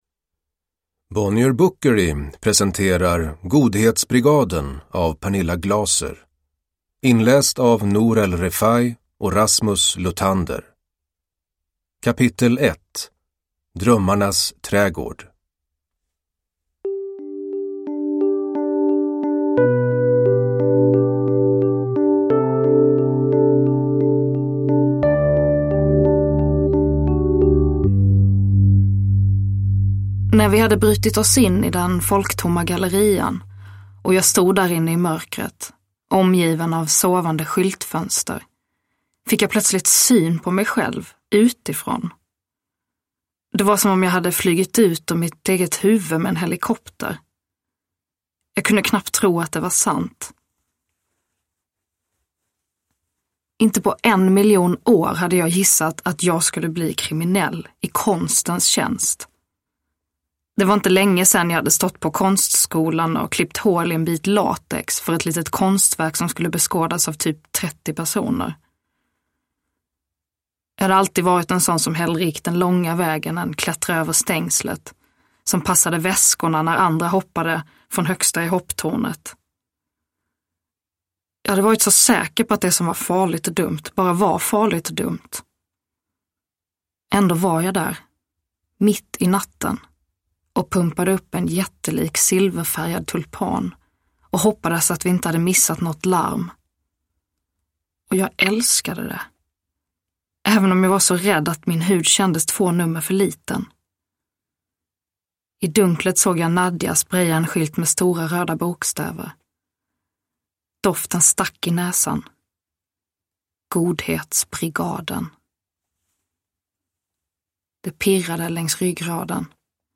Godhetsbrigaden. S1E1, Drömmarnas trädgård – Ljudbok